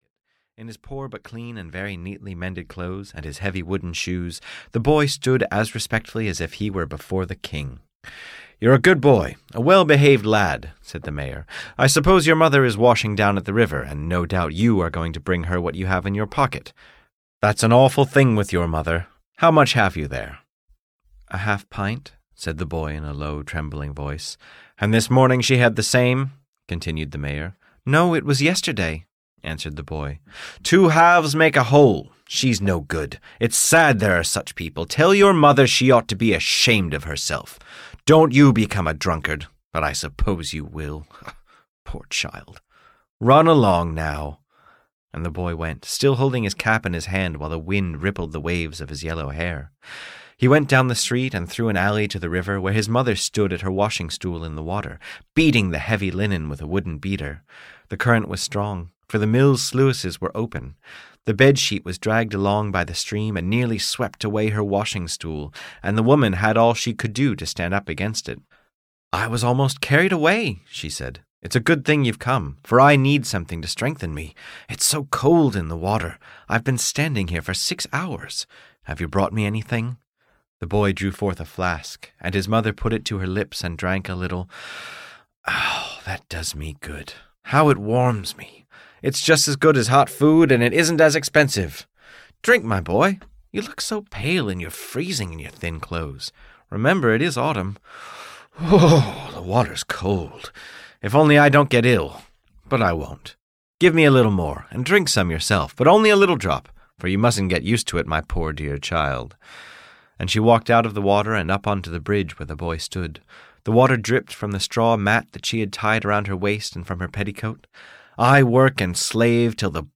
She Was Good for Nothing (EN) audiokniha
Ukázka z knihy